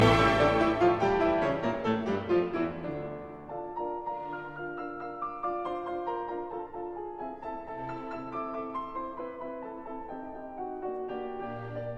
Dans un bel effet de surprise, l’orchestre énonce un unisson à la dominante mi et est suivi directement par l’entrée du piano solo en grappes d’accords descendants en appogiatures qui, en moins de trois mesures, orchestra tacet, imposent la tonalité de la mineur.